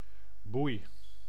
Ääntäminen
Synonyymit ketting keten kluister ton baken Ääntäminen : IPA: [buj] Haettu sana löytyi näillä lähdekielillä: hollanti Käännös Konteksti Substantiivit 1. buoy merenkulku 2. shackle Suku: f .